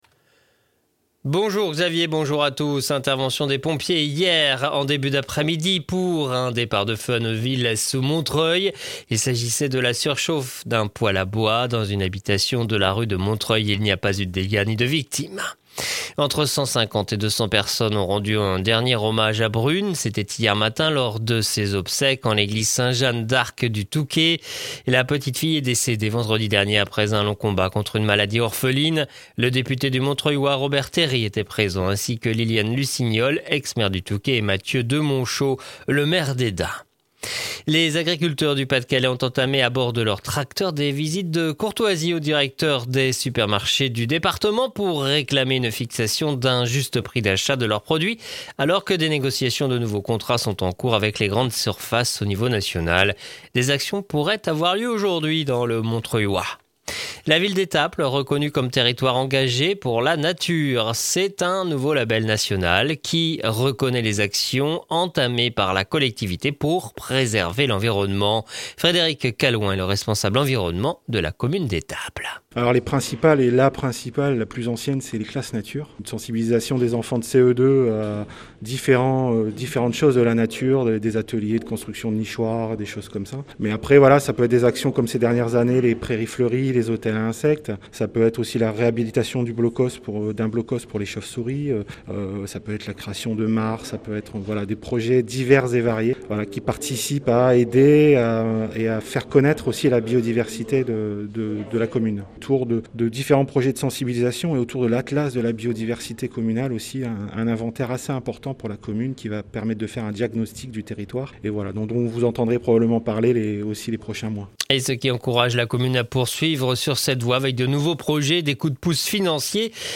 Le journal du Jeudi 25 février dans le Montreuillois